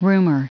Prononciation du mot rumour en anglais (fichier audio)
Prononciation du mot : rumour